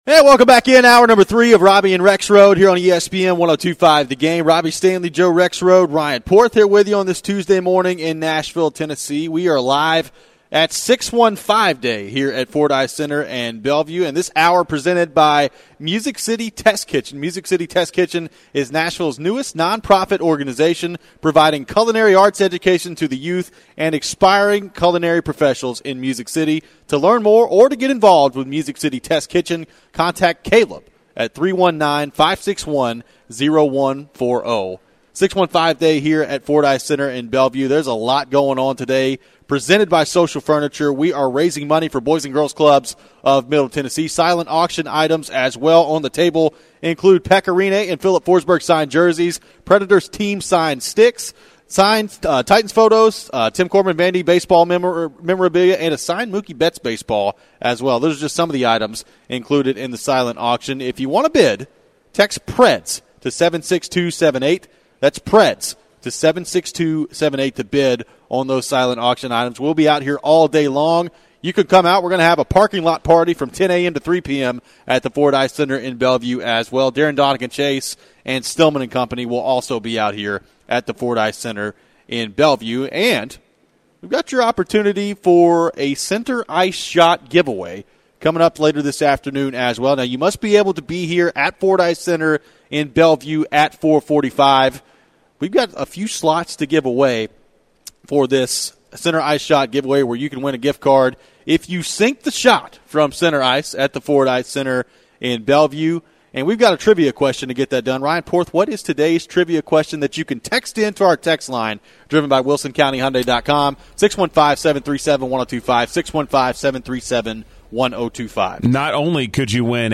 the latest from Aaron Rodgers and more during the third hour of Tuesday's show at the 615 Day Celebration presented by Social Furniture!